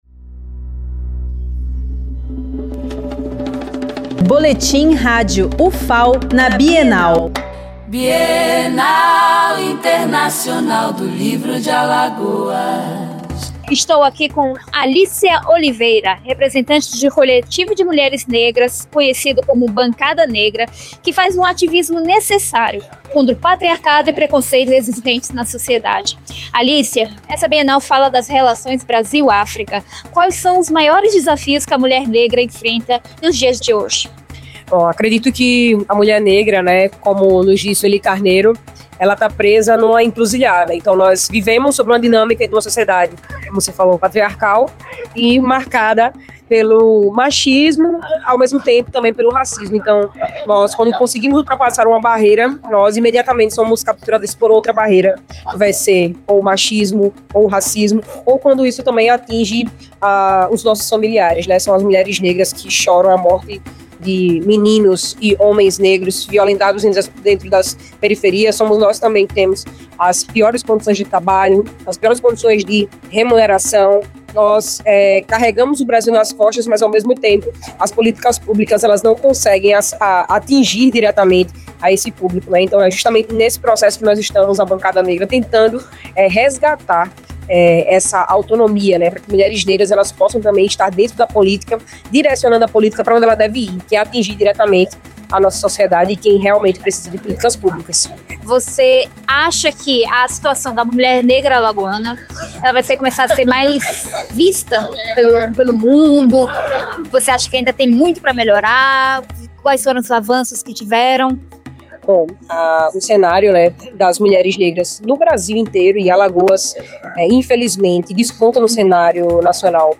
Flashes com informações da 11ª Bienal Internacional do Livro de Alagoas, realizada de 31 de outubro a 9 de novembro de 2025